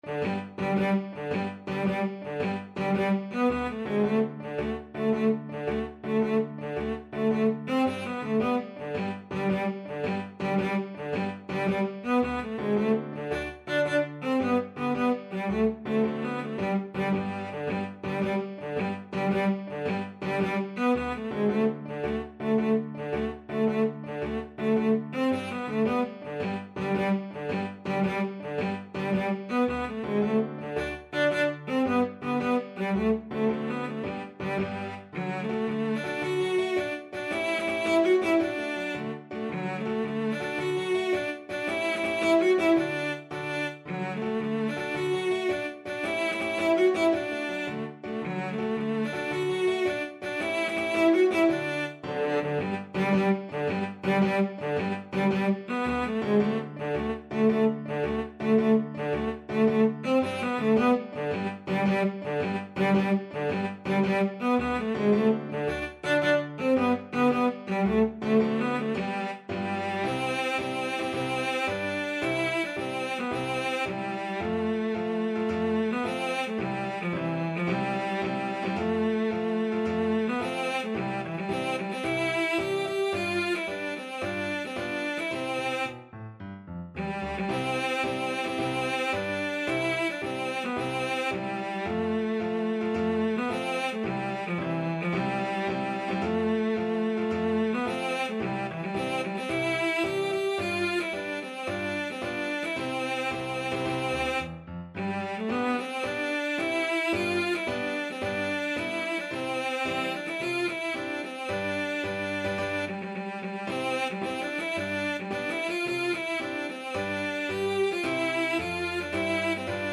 Classical Piefke, Johann Gottfried Koniggratzer March Cello version
6/8 (View more 6/8 Music)
G major (Sounding Pitch) (View more G major Music for Cello )
Classical (View more Classical Cello Music)